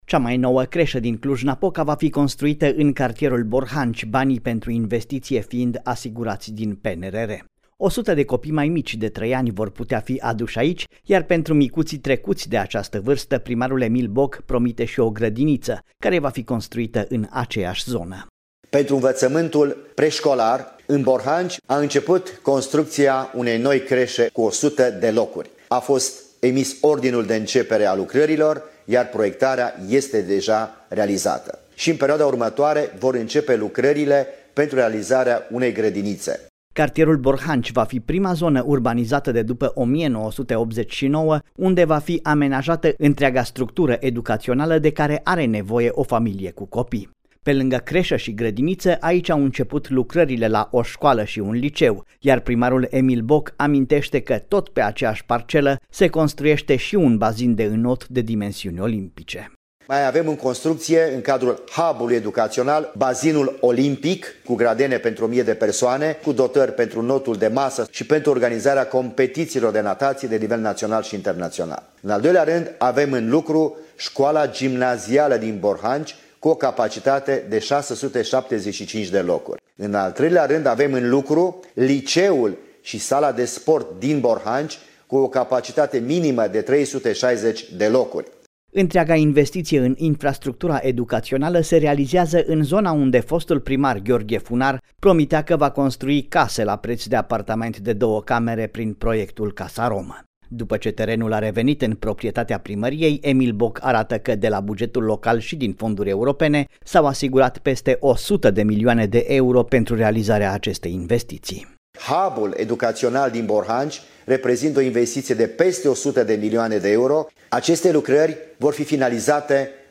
reportaj-cresa-Borhanci.mp3